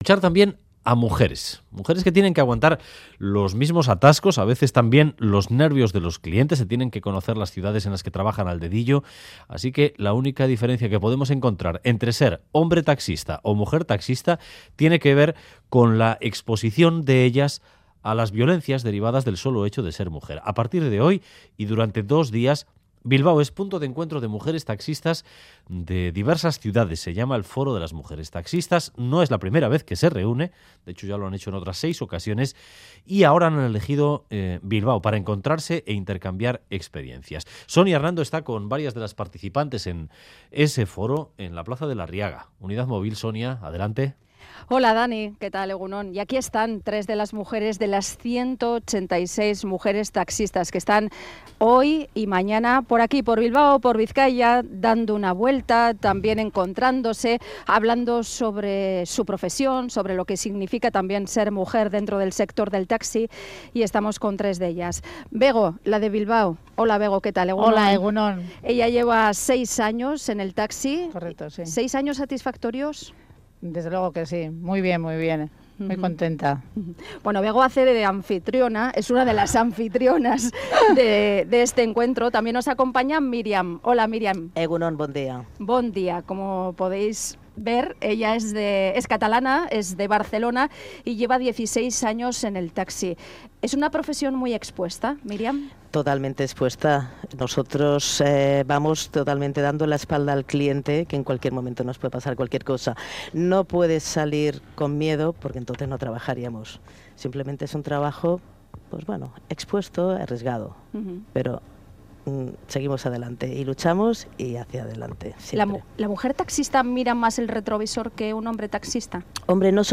son 3 mujeres taxistas que nos han contado su experiencia en 'Boulevard'